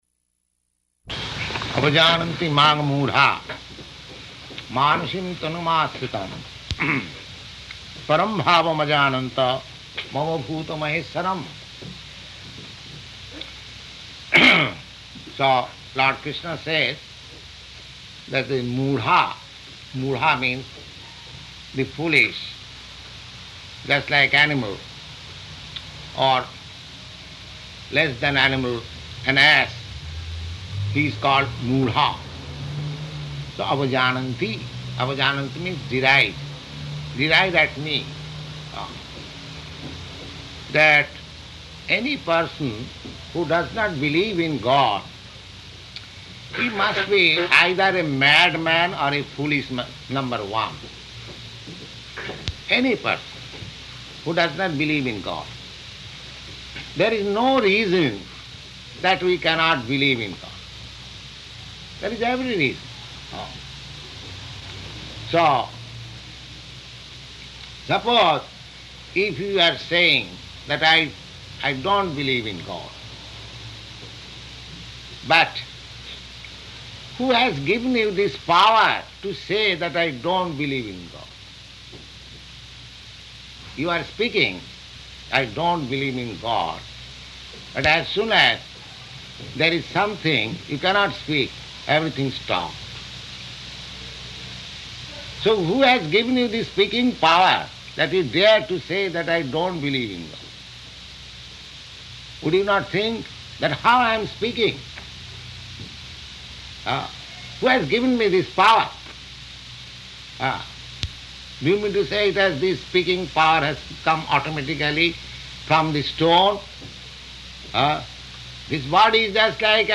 Type: Bhagavad-gita
Location: New York